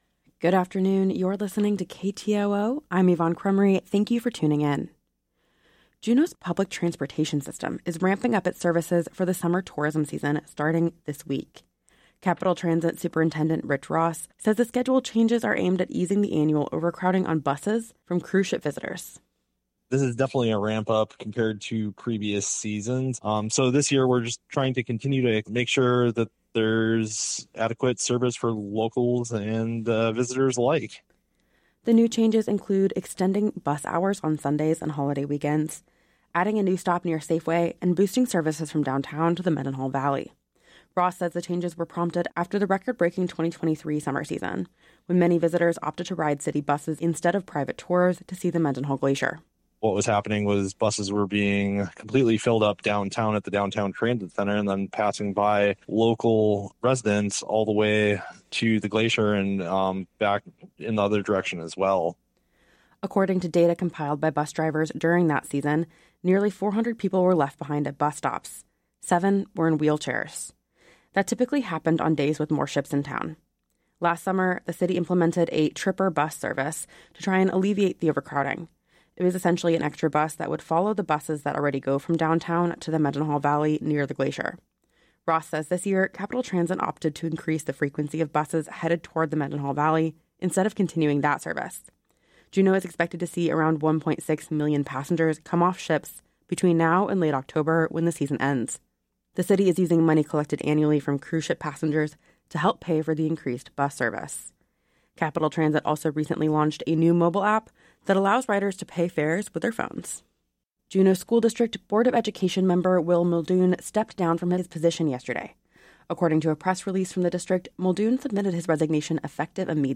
Newscast – Tuesday, April 22, 2025 - Areyoupop